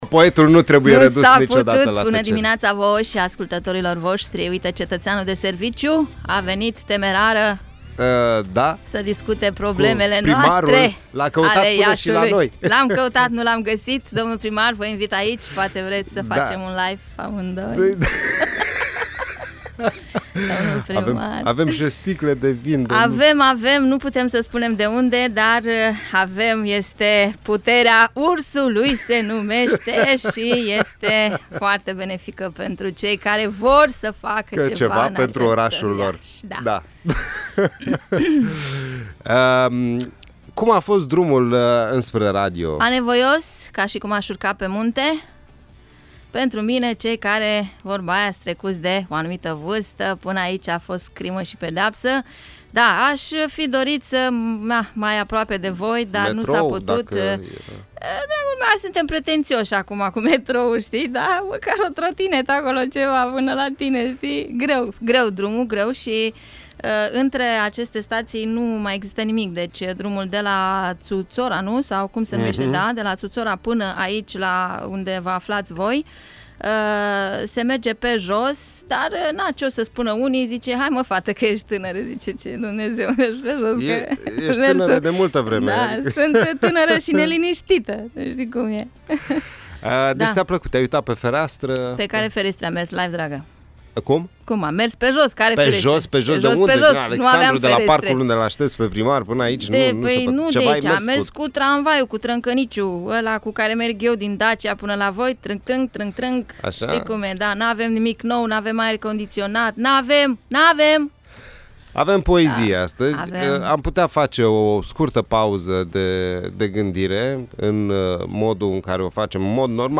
Interviu.
S-au recitat poezii și s-a vorbit despre traficul din Iași și problemele din cartierul Dacia și nu numai.